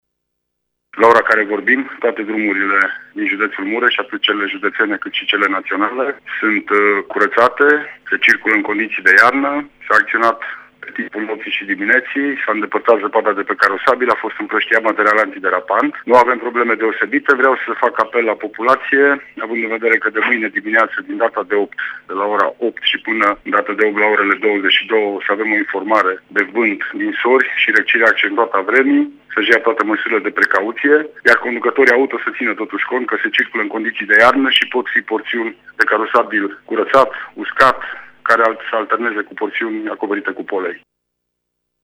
Prefectul judeţului Mureş, Lucian Goga, atrage însă atenţia asupra faptului că în cursul zilei de mâine judeţul Mureş se află sub atenţionare de viscol, ninsori şi temperaturi scăzute: